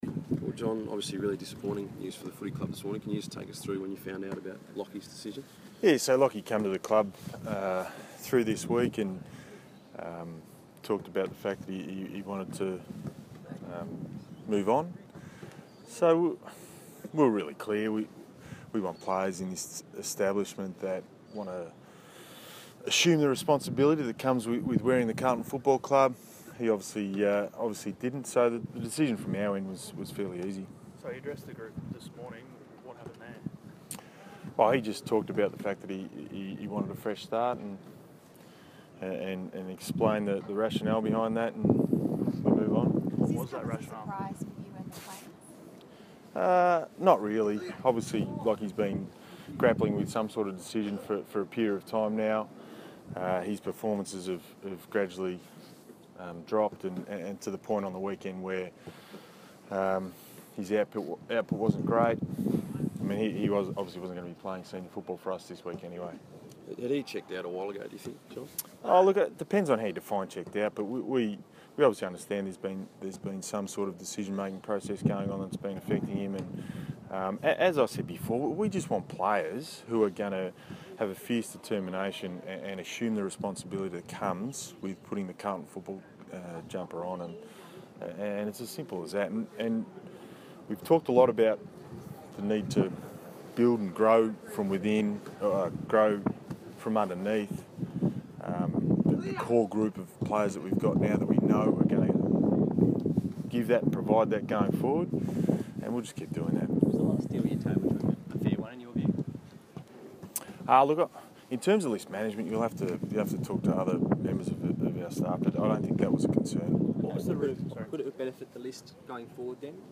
press conference
speaks to the media